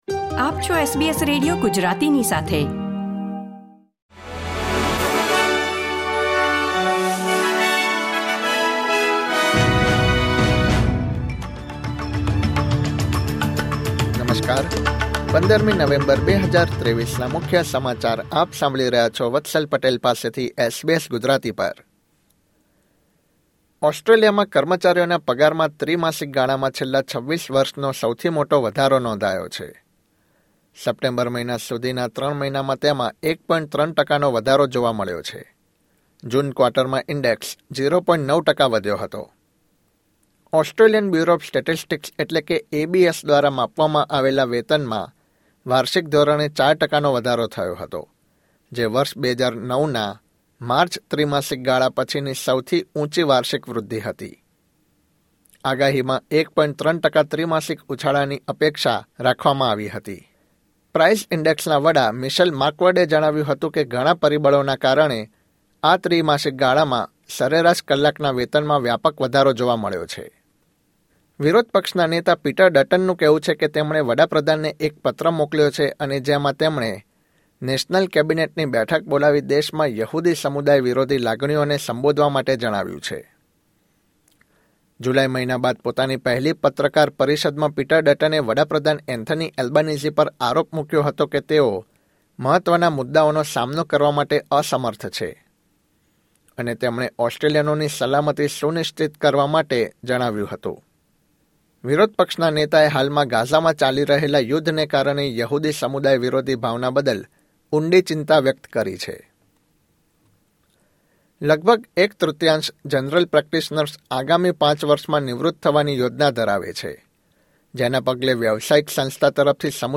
SBS Gujarati News Bulletin 15 November 2023